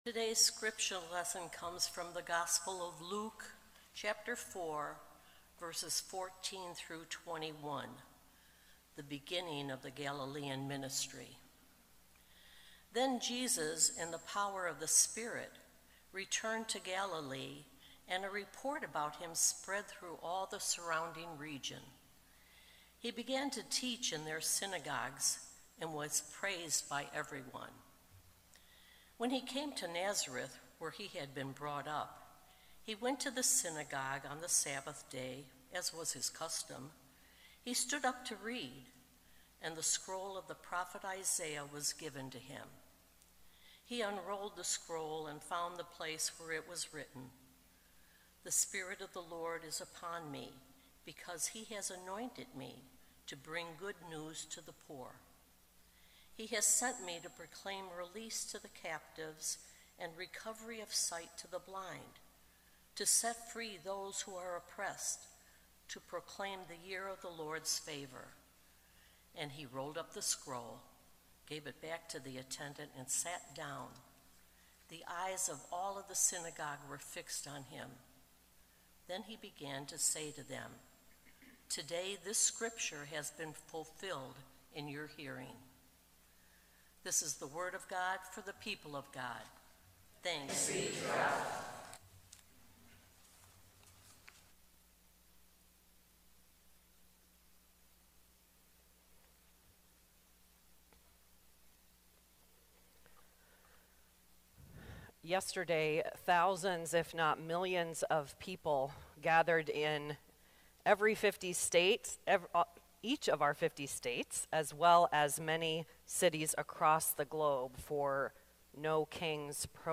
Sermons | Faith United Methodist Church of Orland Park